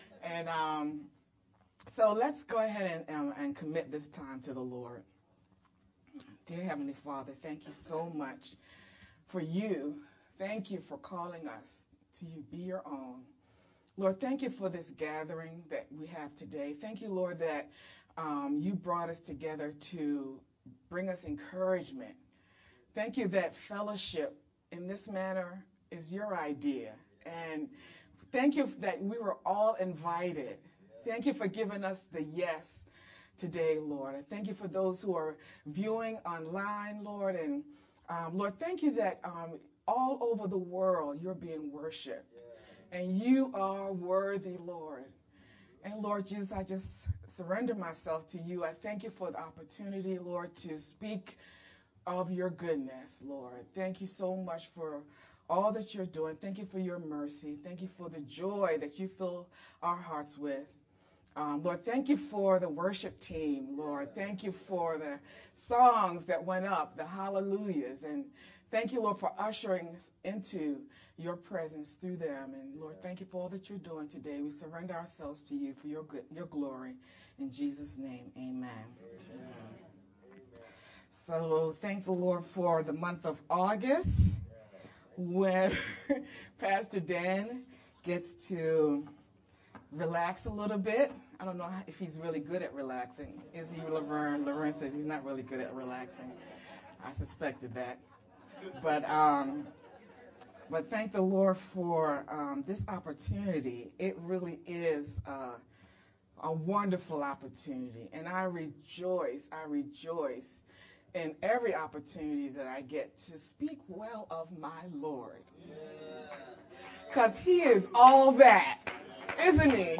August-6-VBCC-Sermon-only-_Converted-CD.mp3